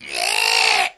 Goblin_Death1.wav